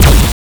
lasershot.wav